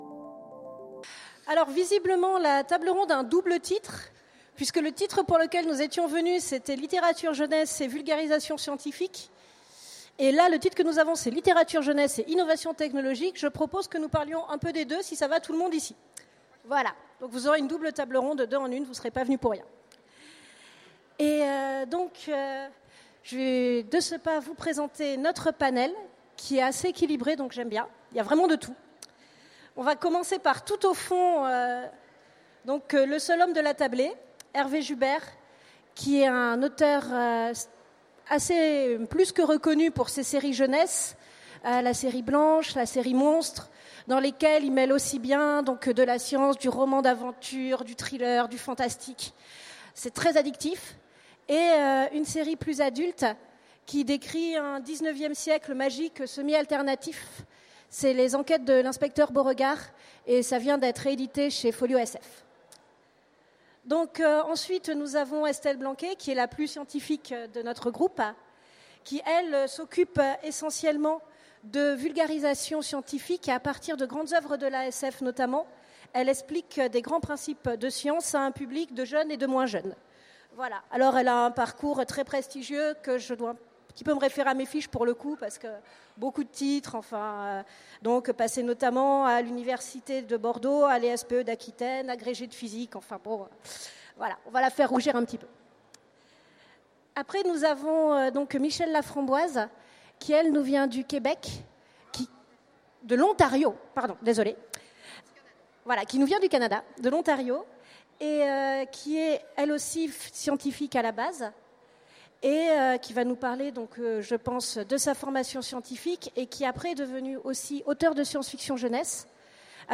Utopiales 2016 : Conférence Littérature jeunesse et vulgarisation scientifique